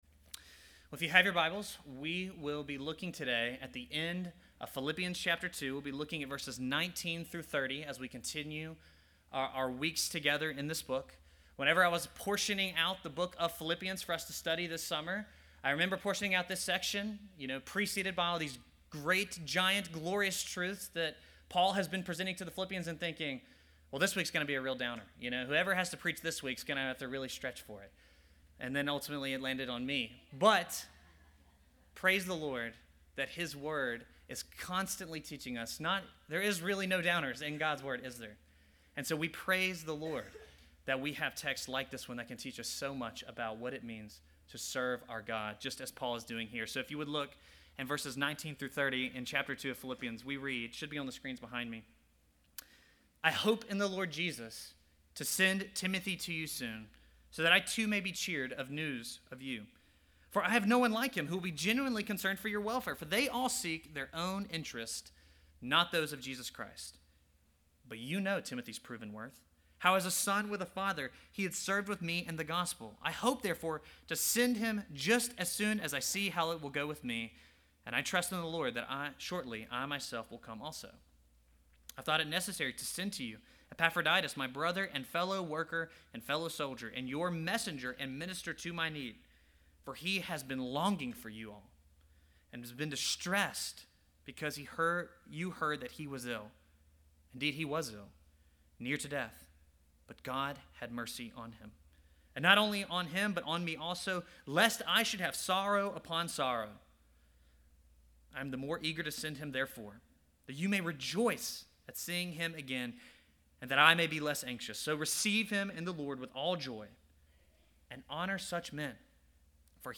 Sermons | Grace Bible Church of Oxford